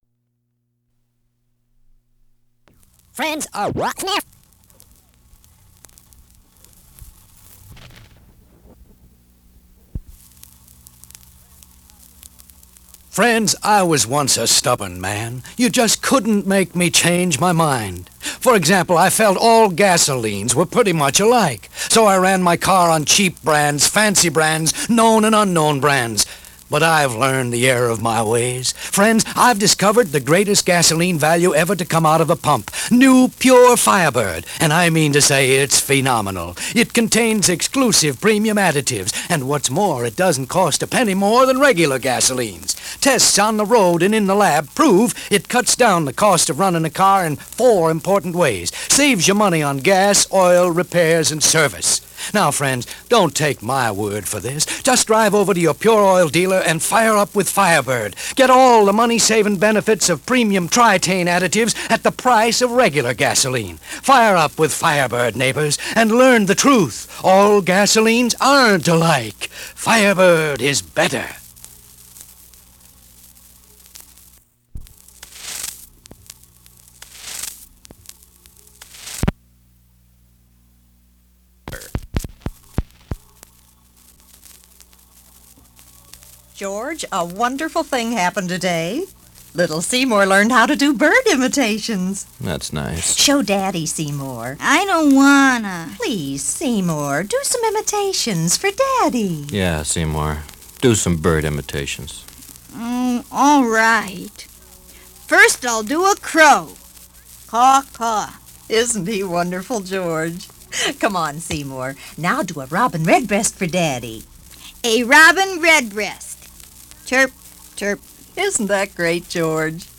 A radio news broadcast presenting a session highlighting an upcoming vote on school bonds. The production includes a school choir announcing the amenities school bonds would cover while piano music plays in the background.